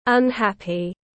Không hài lòng tiếng anh gọi là unhappy, phiên âm tiếng anh đọc là /ʌnˈhæpi/
Unhappy.mp3